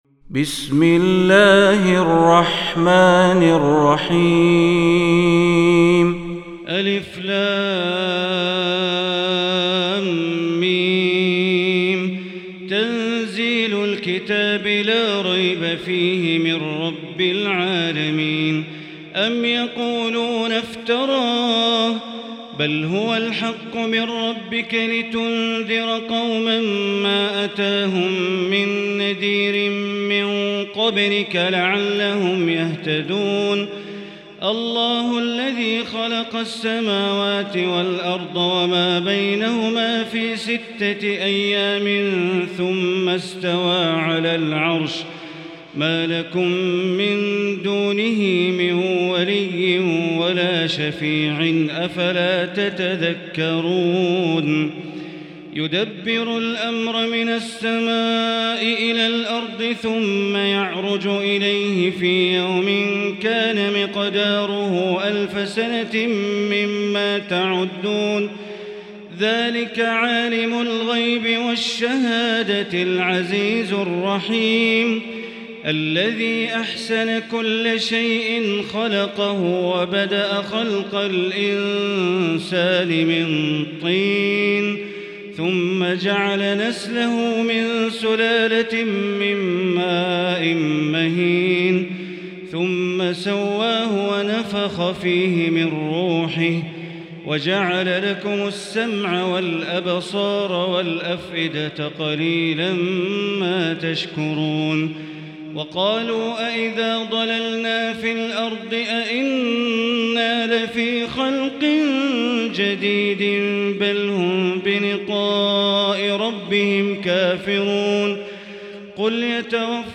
المكان: المسجد الحرام الشيخ: معالي الشيخ أ.د. بندر بليلة معالي الشيخ أ.د. بندر بليلة السجدة